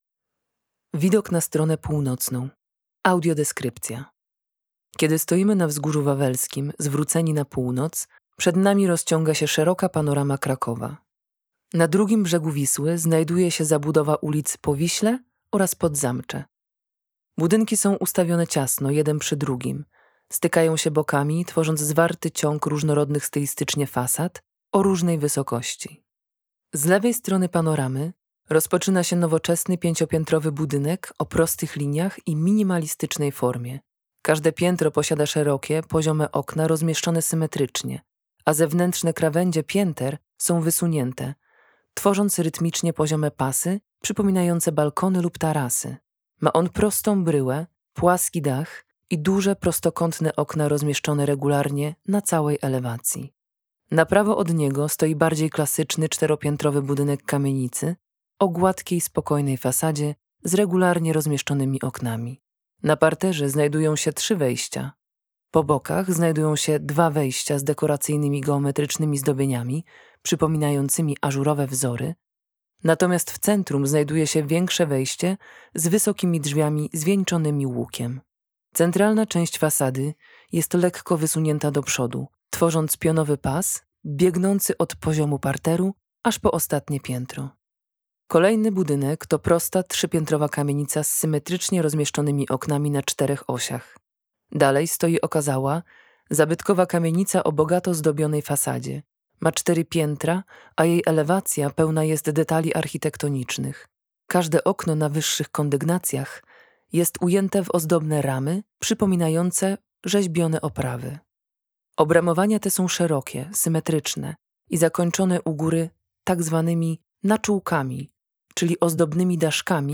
Audiodeskrypcje widoków z Wawelu - PÓŁNOC - Zamek Królewski na Wawelu - oficjalna strona - bilety, informacje, rezerwacje